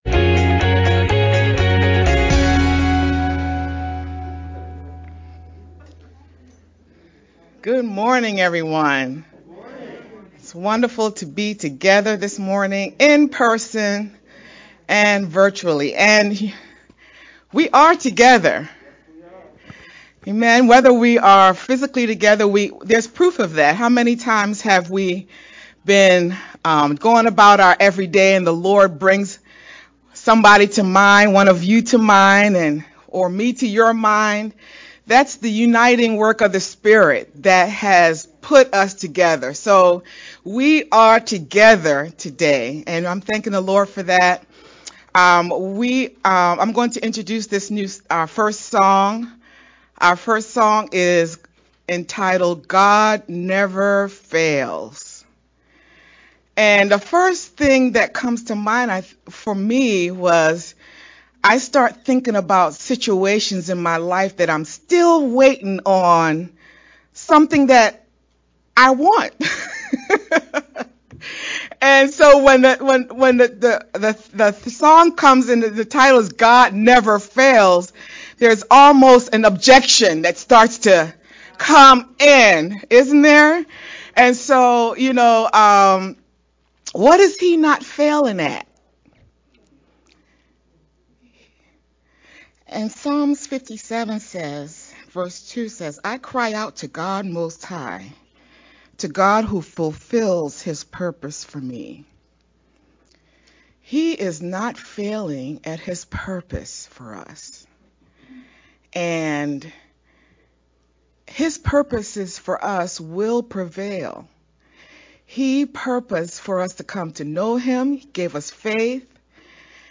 VBCC-April-3rd-Sermon-edited-Mp3-CD.mp3